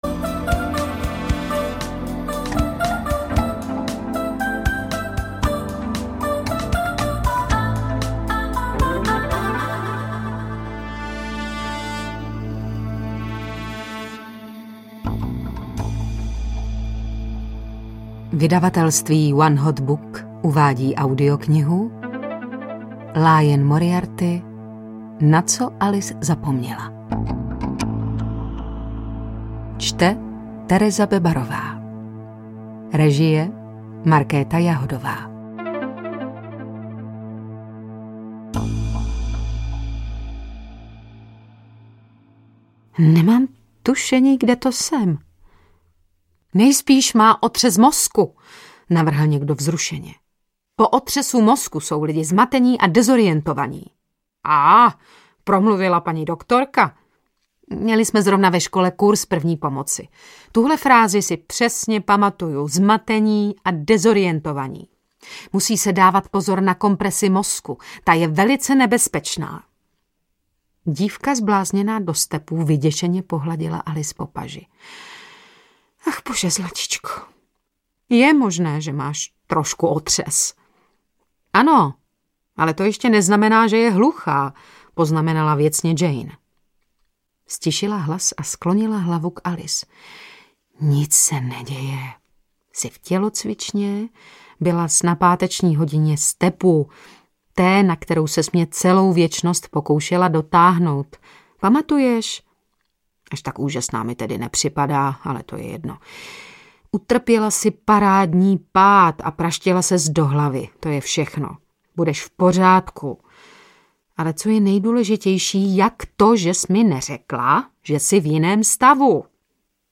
Na co Alice zapoměla audiokniha
Ukázka z knihy
• InterpretTereza Bebarová